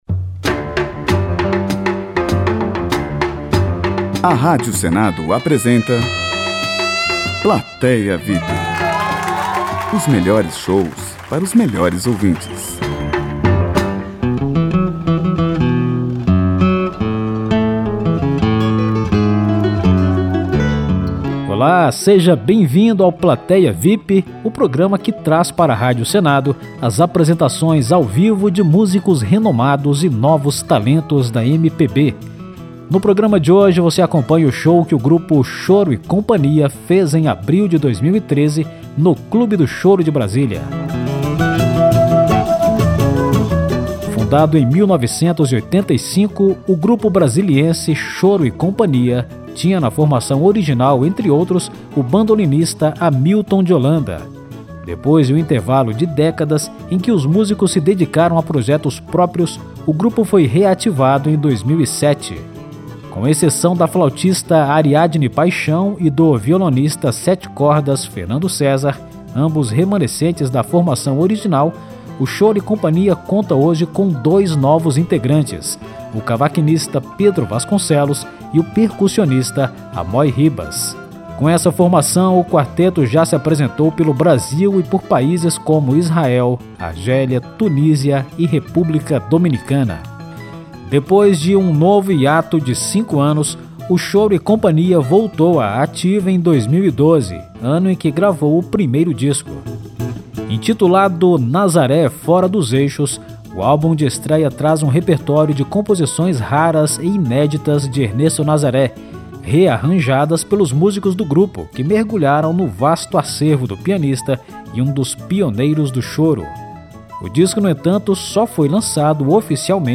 Música Brasileira Choro